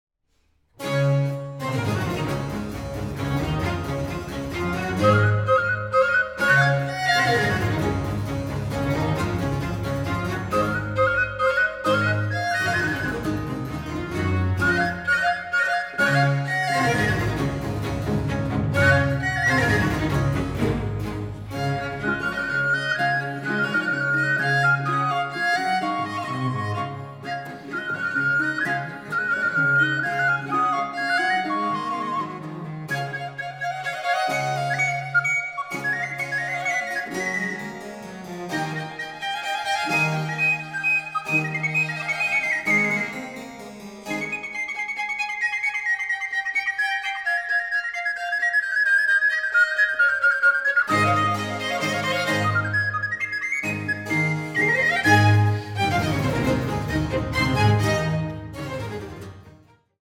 Flöte